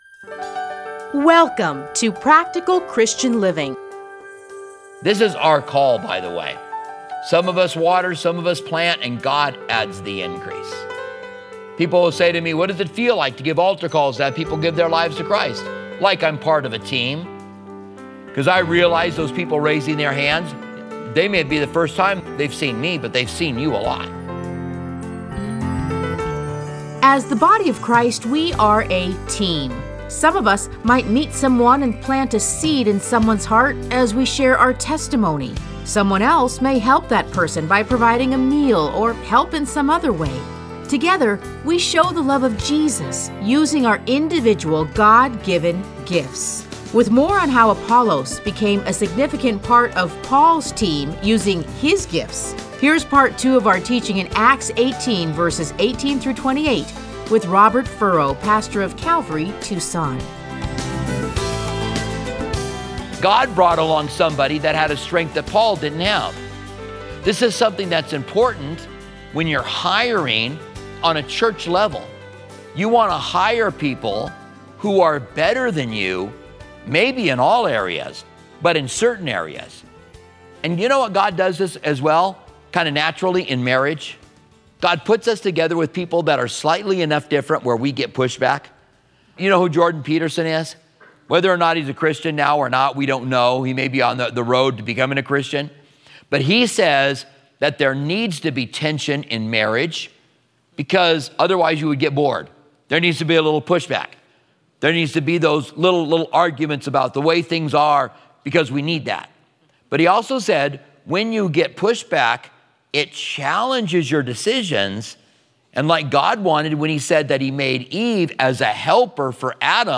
Listen to a teaching from Acts 18:18-28.